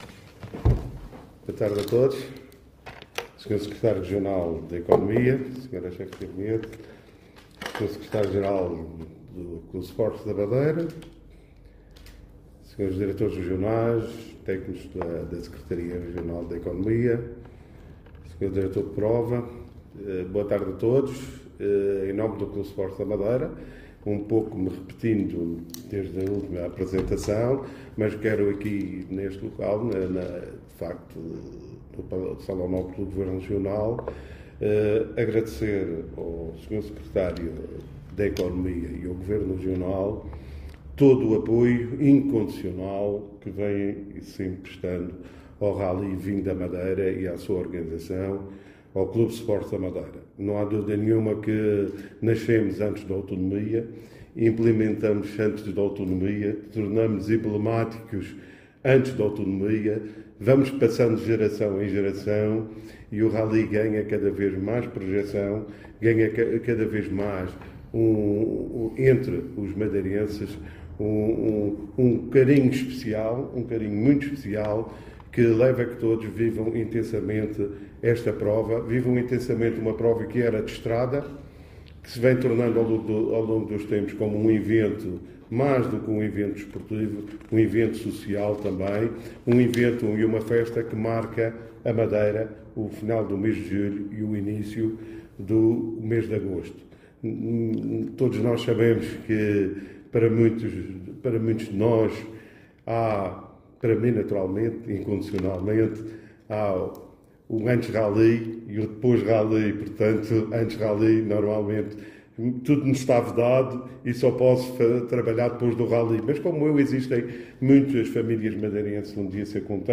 Anexos Conferência de Imprensa (SOM) Contrato Programa RV_1 Contrato Programa RV_2 Contrato Programa RV_3 Contrato Programa RV_4 Descritores Economia Rali da Madeira 2025 Club Sports da Madeira Governo Regional da Madeira